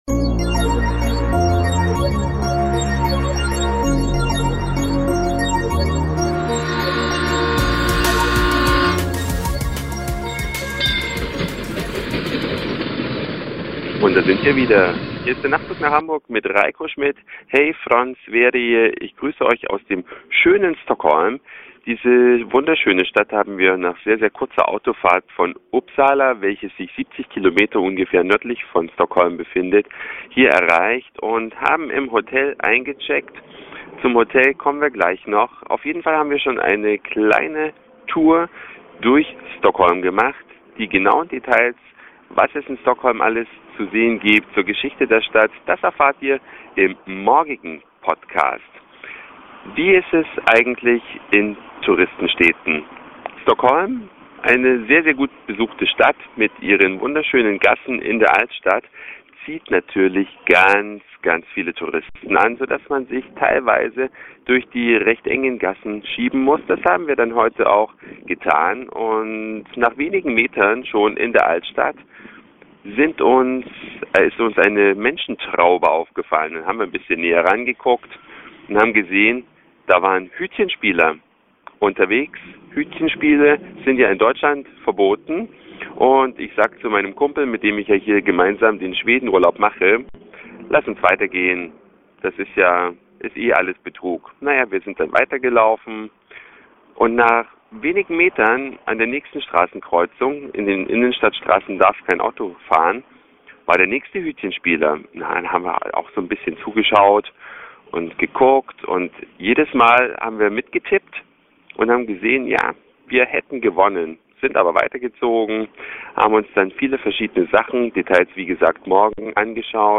Eine Reise durch die Vielfalt aus Satire, Informationen, Soundseeing und Audioblog.
Beim letztgenannten seid Ihr live dabei.